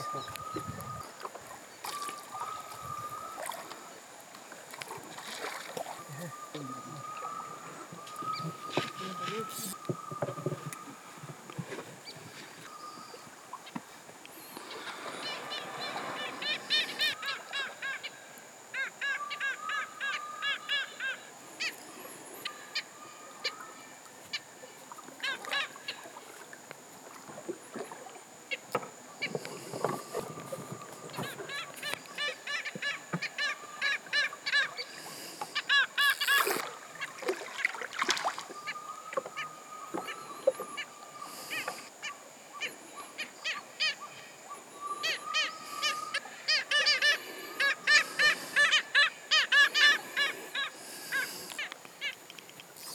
Jungle River Sounds
Category 🌿 Nature
birds-singing chirping field-recording Jungle monkey-noise water-lapping sound effect free sound royalty free Nature